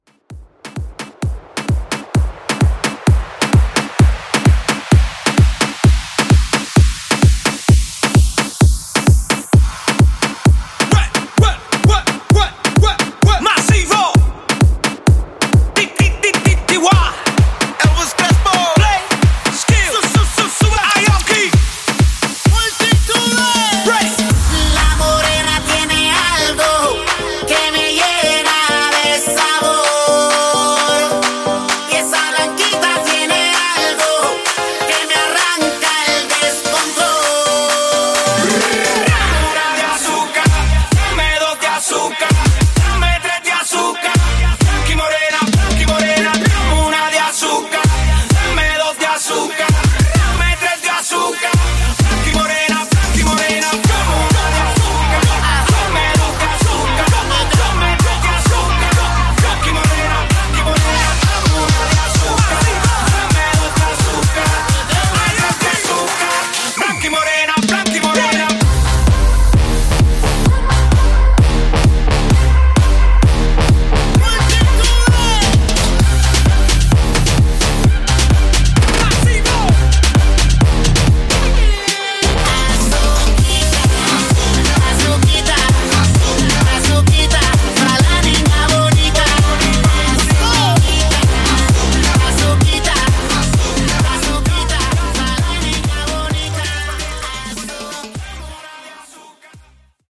In-Outro Mixshow)Date Added